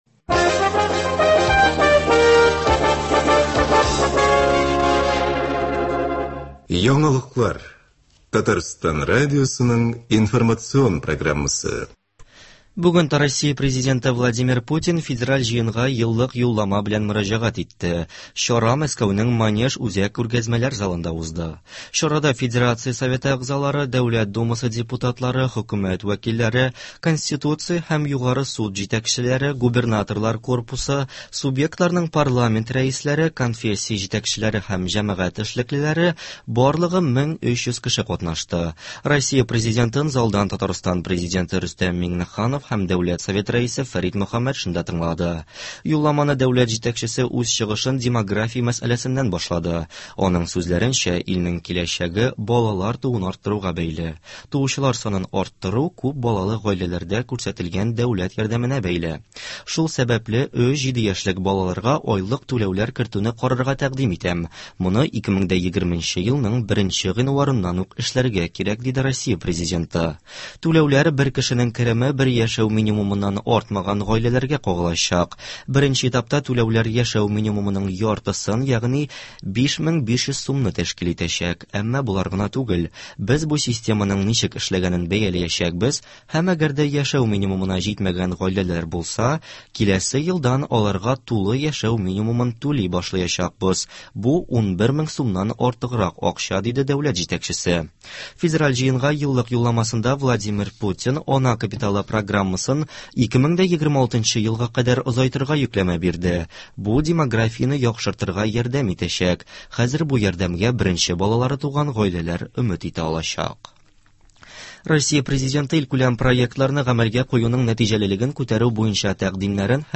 Яңалыклар. 15 гыйнвар.